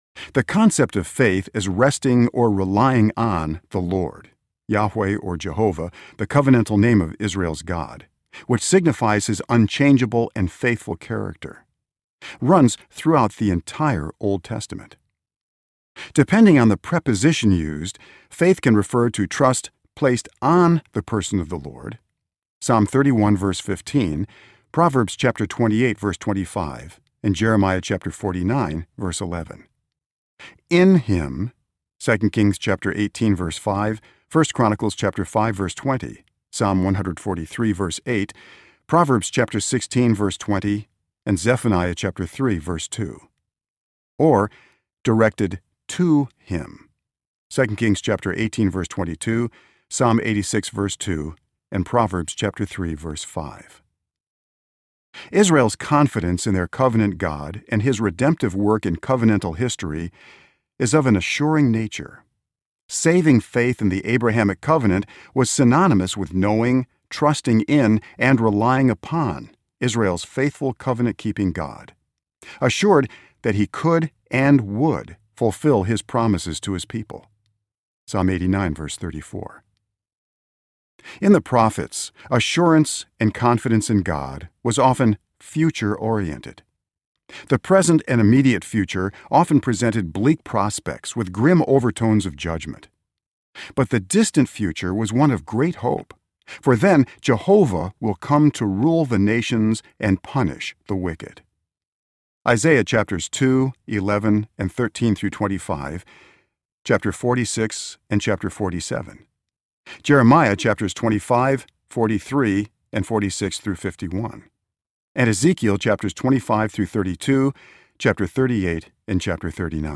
Knowing and Growing in Assurance of Faith Audiobook
Narrator
7.92 Hrs. – Unabridged